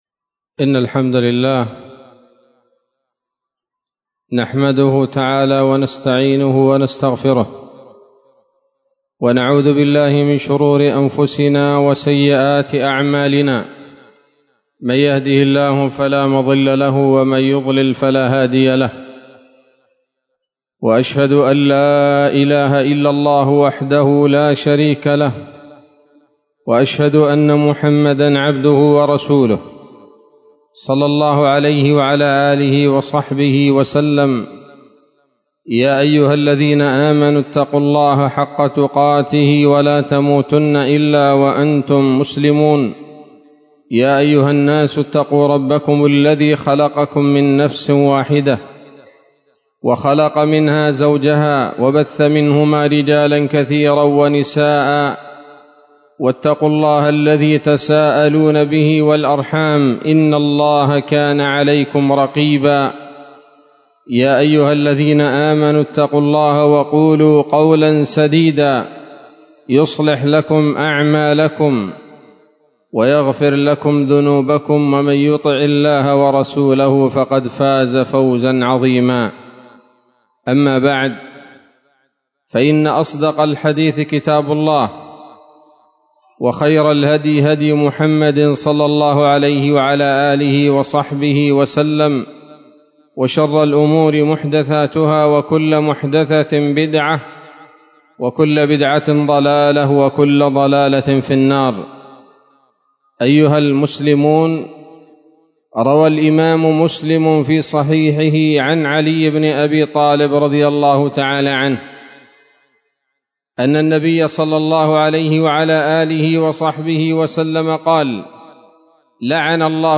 خطبة جمعة بعنوان: (( تحذير العقال من فتنة الأقيال )) 5 رجب 1444 هـ، دار الحديث السلفية بصلاح الدين